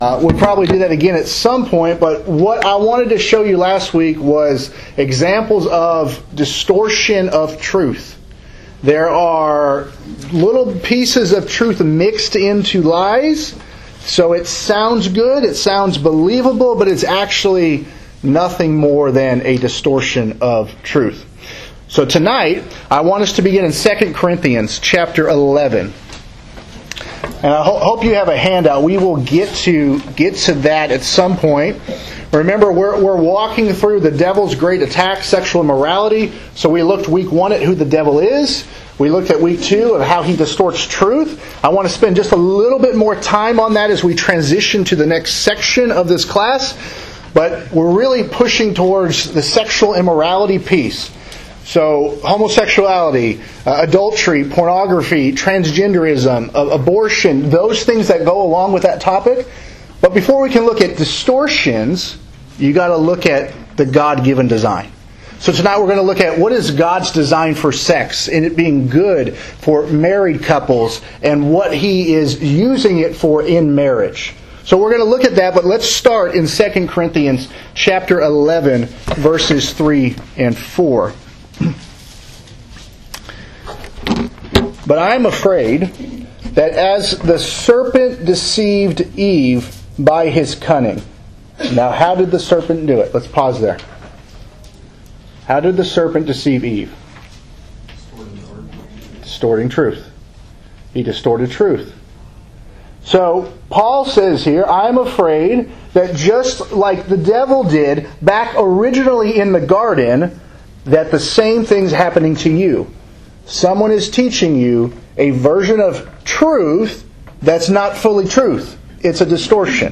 Sermons | Windsong church of Christ
BIBLE CLASS - God's Design for Sex